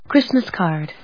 Chrístmas càrd